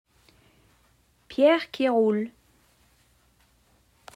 How to pronounce Pierre-Qui-Roule